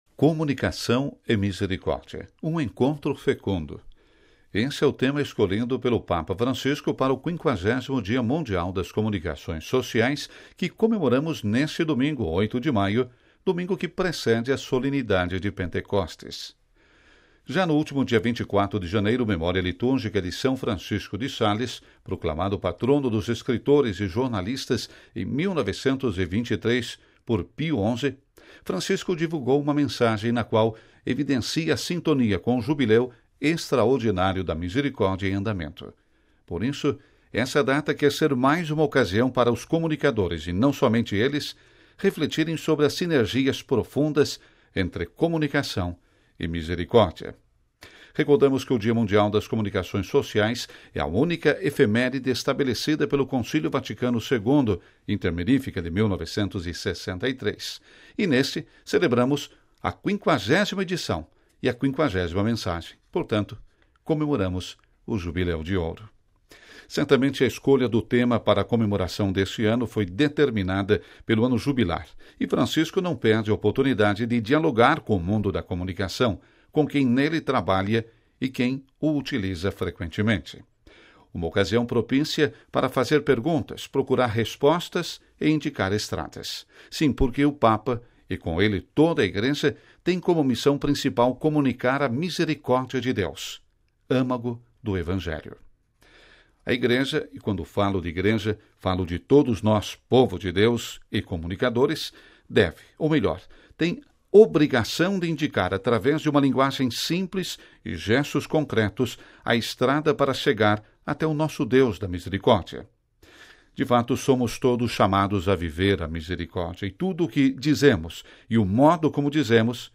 Editorial: Comunicar com misericórdia